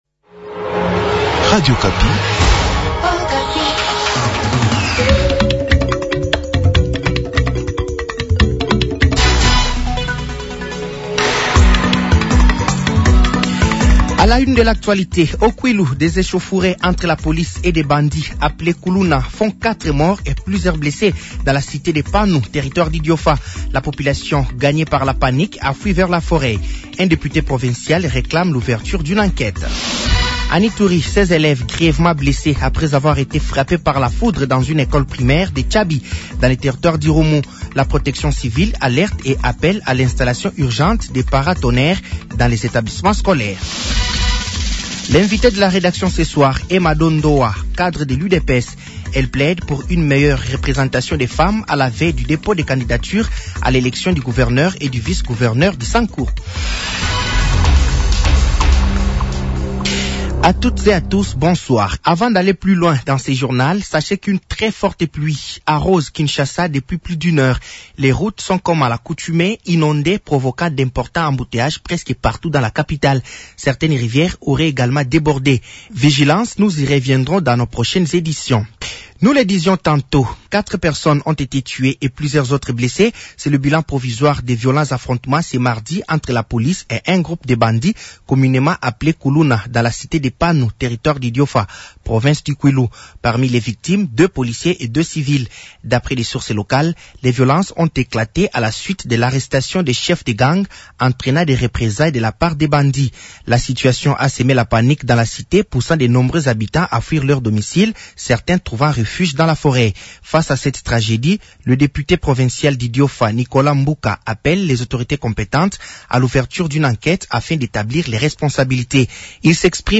Journal français de 18h de ce mercredi 04 février 2026